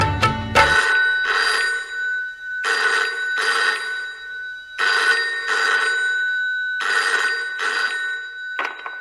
Android, Klassisk Telefon, Klassisk